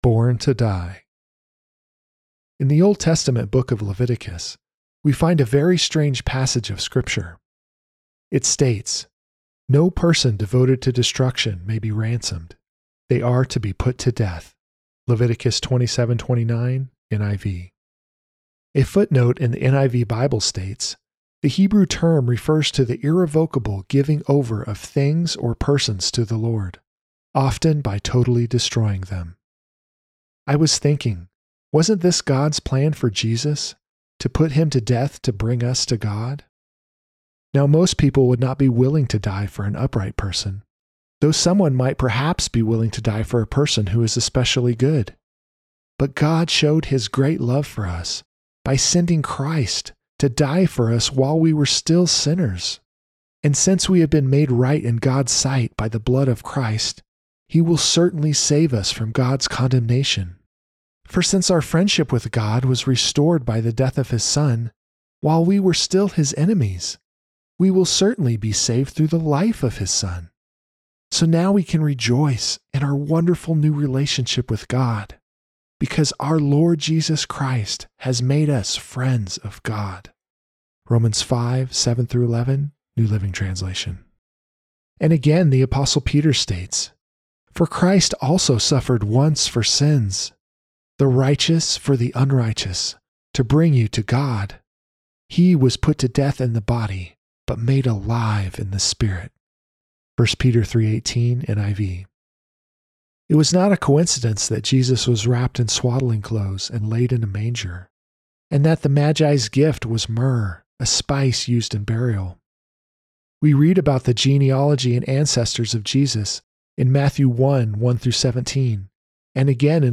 Audio Lesson